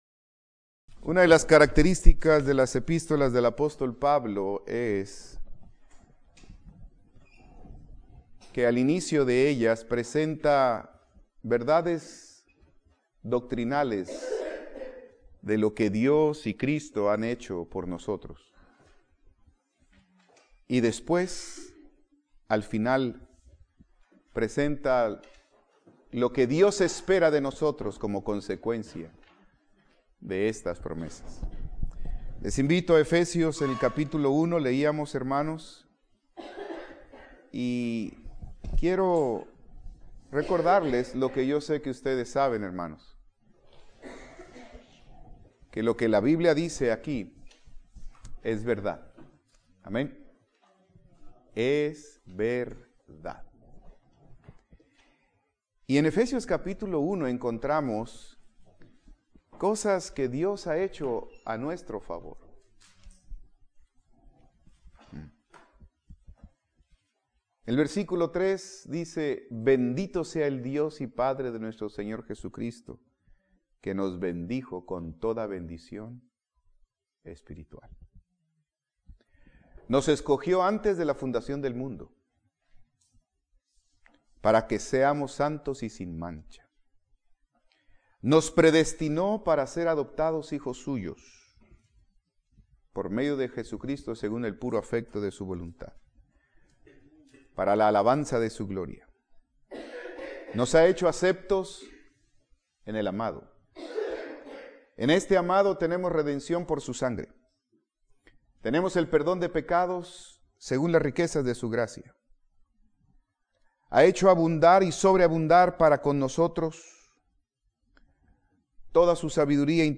Servicio de Nuevo Año